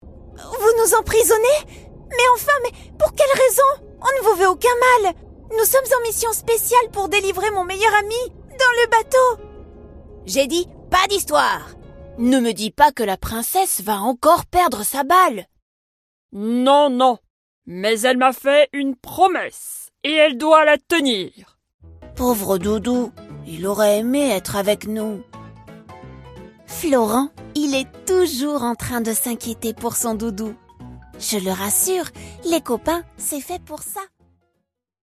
Tief, Verspielt, Vielseitig, Sanft, Corporate
Persönlichkeiten